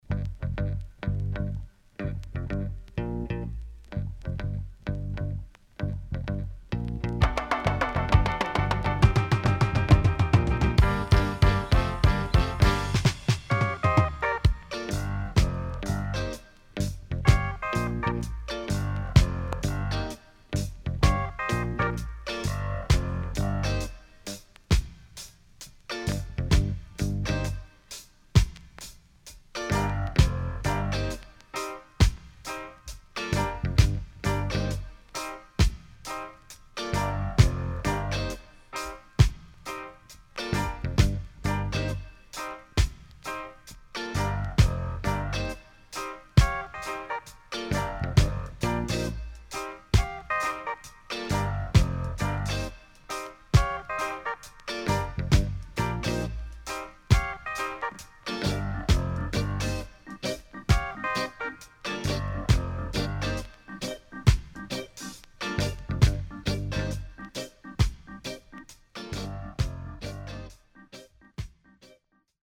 Very Cool Vocal & Good Inst.W-Side Good.Good Condition
SIDE A:少しチリノイズ入りますが良好です。